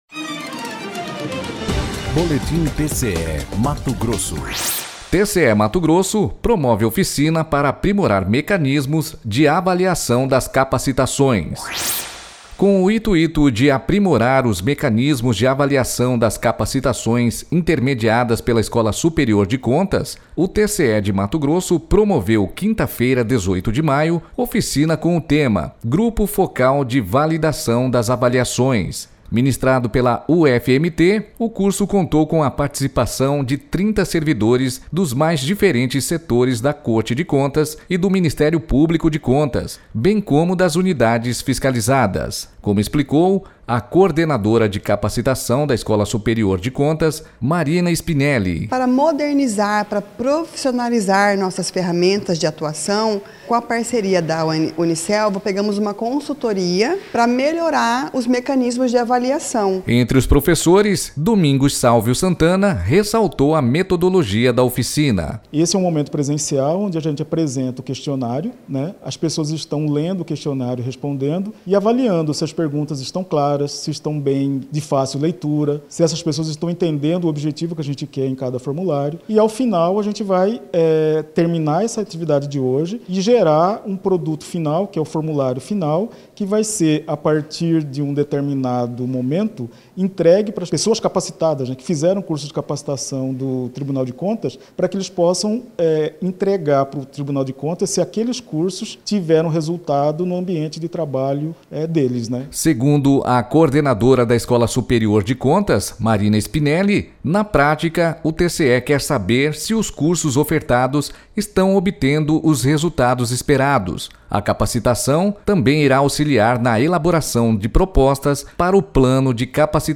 professor